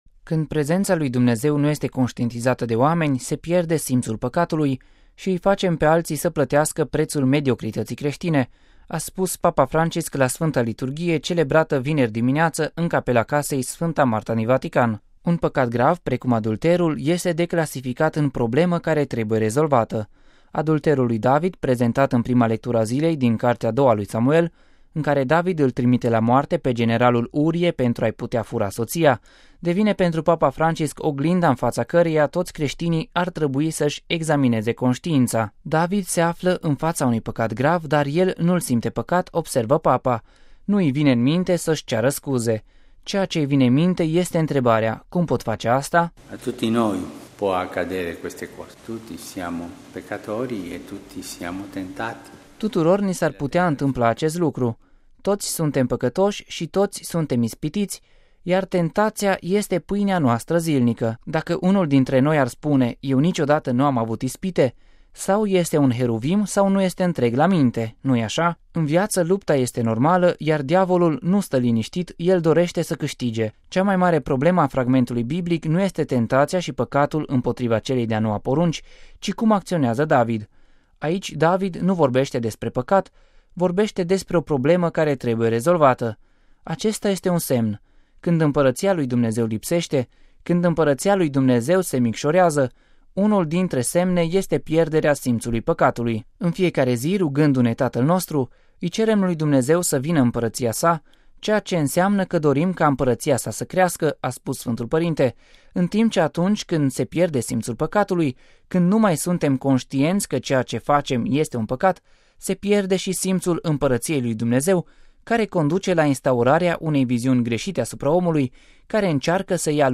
(RV 31 ian 2014) Când prezenţa lui Dumnezeu nu este conştientizată de oameni, „se pierde simţul păcatului” şi îi facem pe alţii să plătească preţul „mediocrităţii creştine”, a spus Papa Francis la Sf. Liturghie celebrată vineri dimineaţă în Capela Casei Sf. Marta din Vatican.